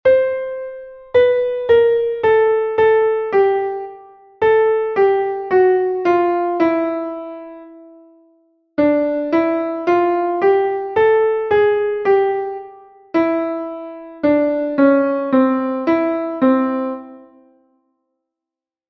Task 2 descending chromatic semitone
ejercicio_entonacion_e_identificacion_semitono_cromatico_descendente.mp3